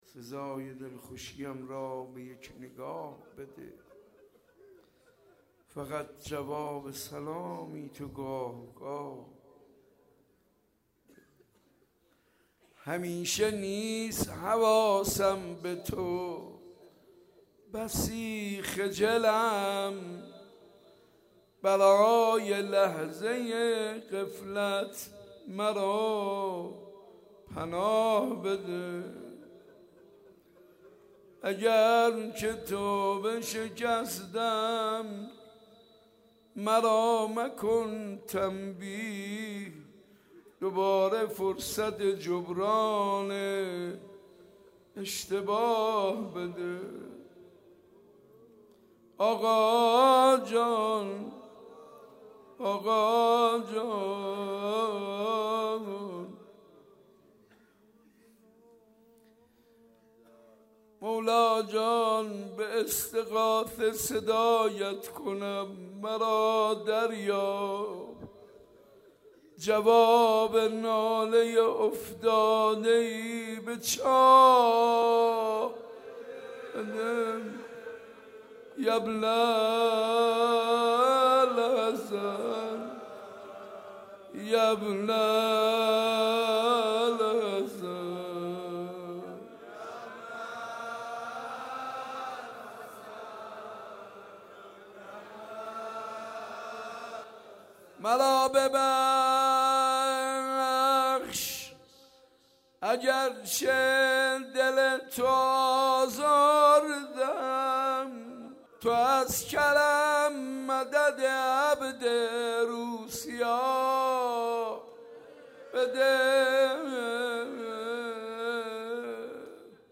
حاج منصور ارضی/مناجات با امام زمان(عج)